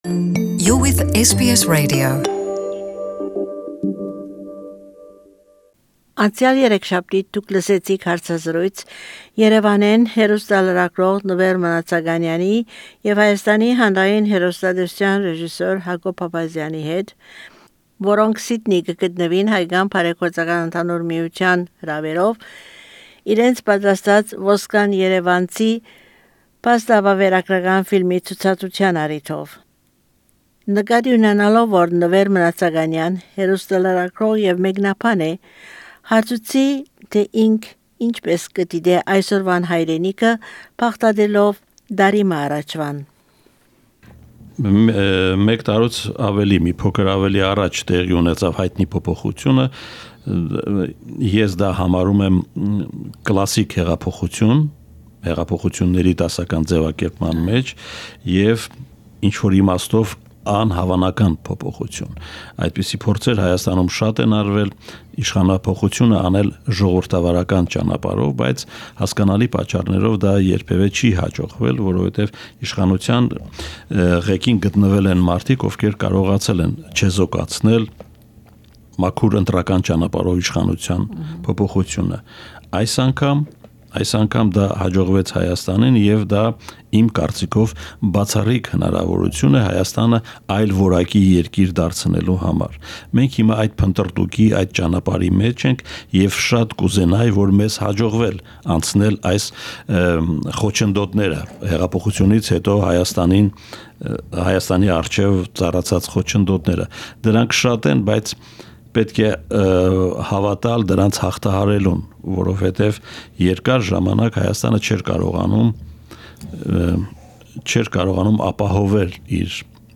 interview about life in present day Armenia